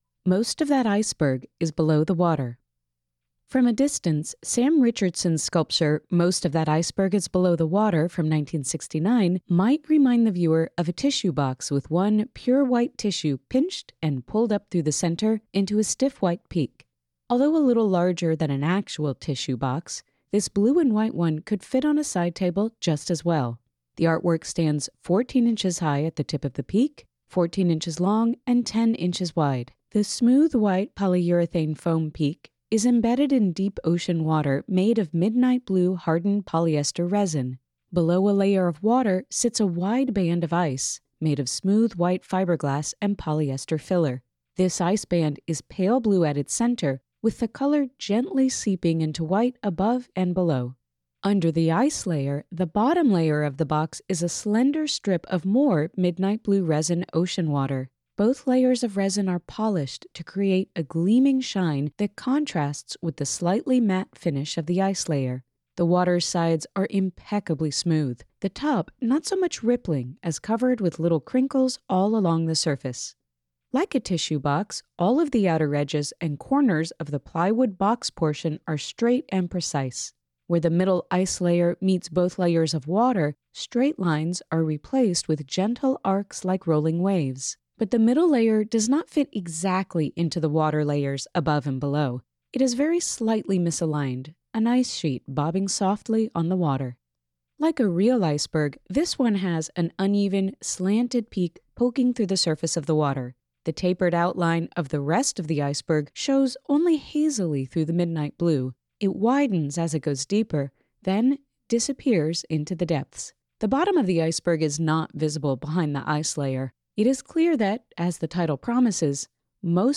Audio Description (02:03)